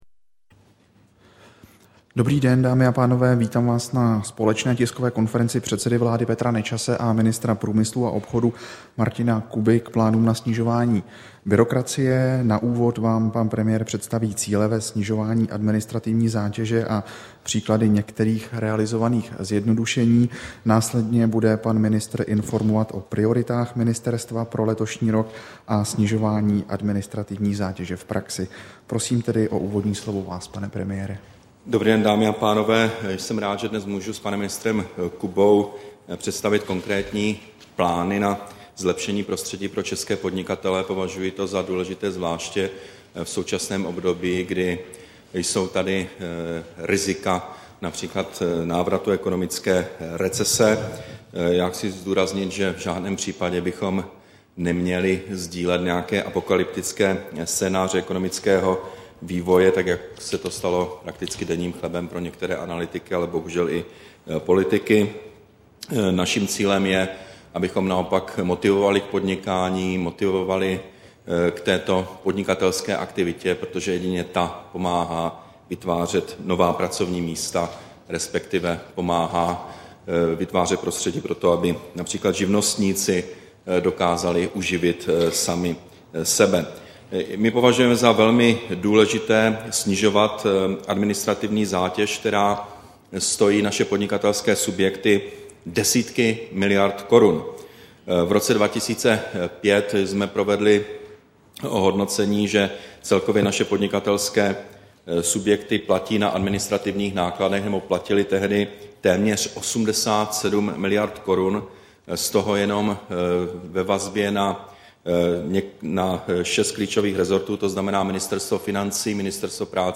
Tisková konference premiéra Petra Nečase a ministra průmyslu a obchodu Martina Kuby k plánům na snižování byrokracie do roku 2014, 10. ledna 2012